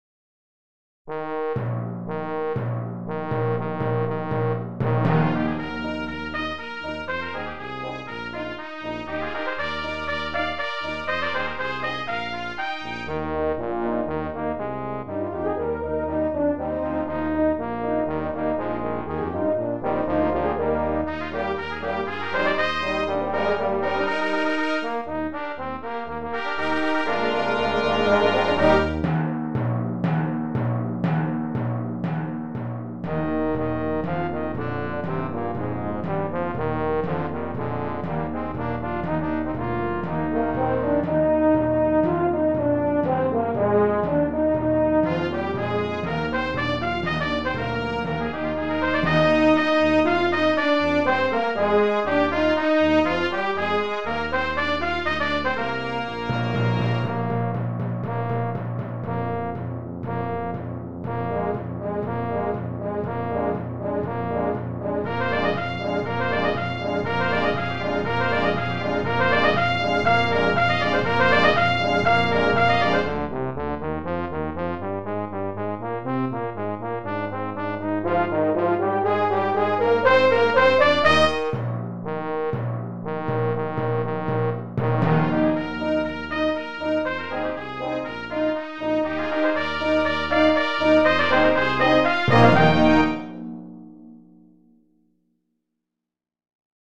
Voicing: Brass Ensemble